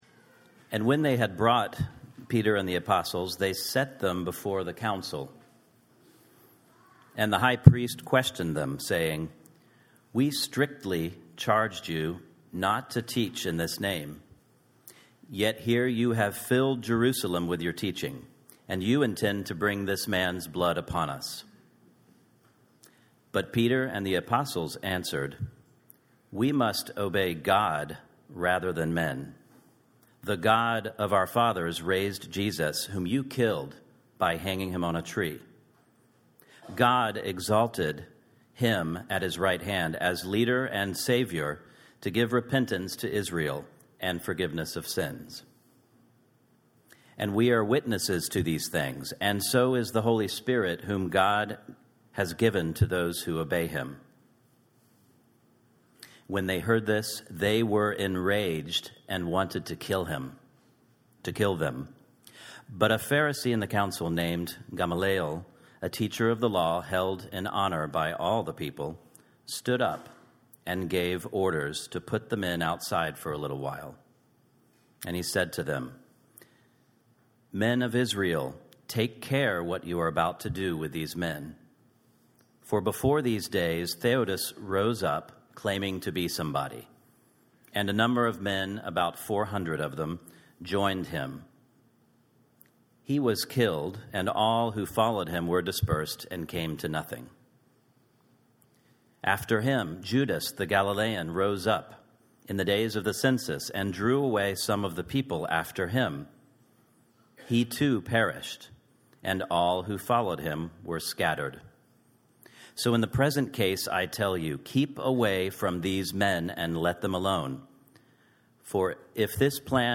sermon-audio-5.31.15.mp3